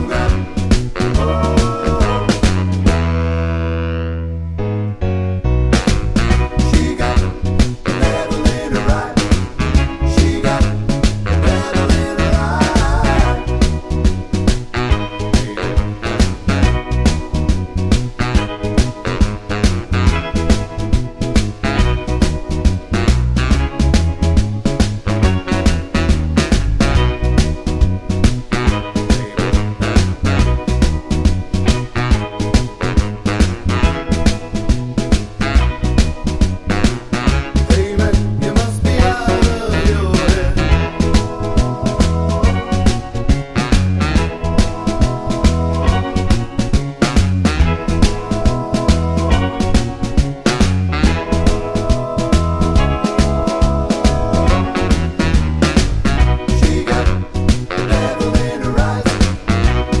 no Backing Vocals Glam Rock 2:43 Buy £1.50